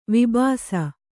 ♪ vibhāsa